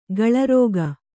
♪ gaḷa rōga